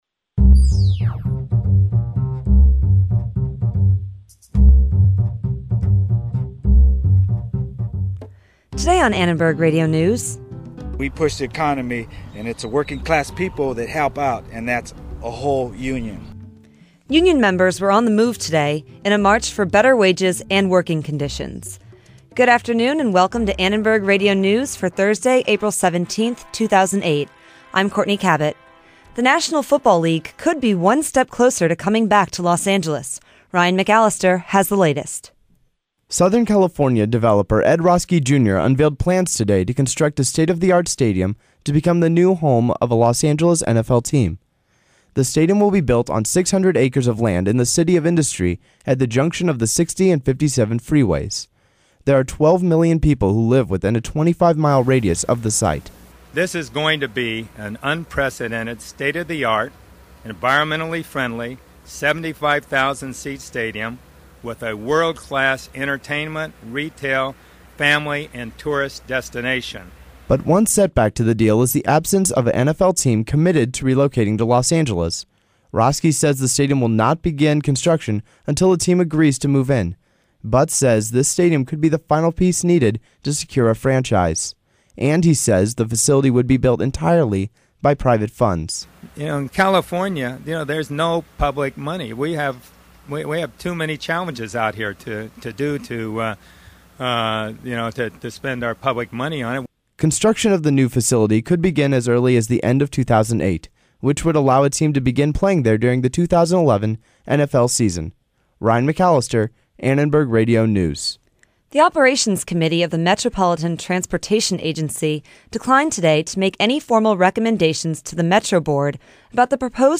ARN Live Show - April 17, 2008 | USC Annenberg Radio News